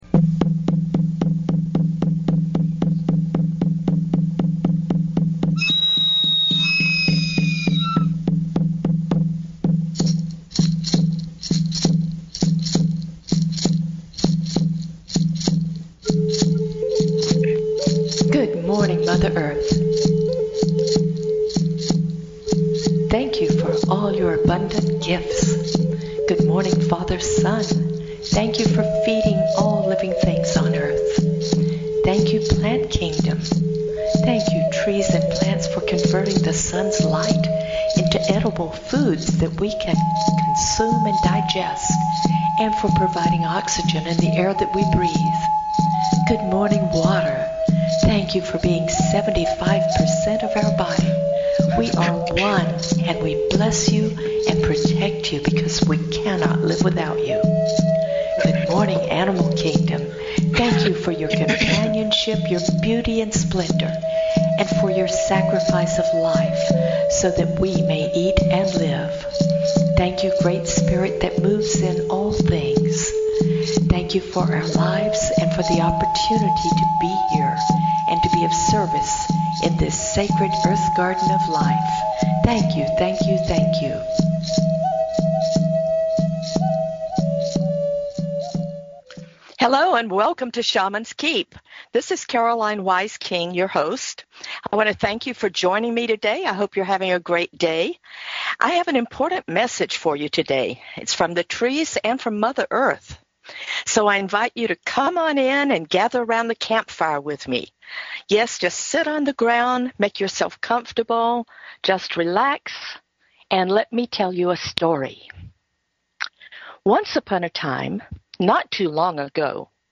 Talk Show Episode, Audio Podcast, LIFE OF TREES and Amazing Facts about Ancient Trees on , show guests , about Ancient Trees,Mother Earth,World Tree,Nordic Beliefs,Tree of Life,Tree Species,Gingko Biloba,Ancestors,goddess,Breath of Life, categorized as Earth & Space,Plant & Animals,Health & Lifestyle,History,Spiritual,Animal & Plant Communications,Shamanism
It is told like a campfire storyteller would tell it, and has a beautiful message.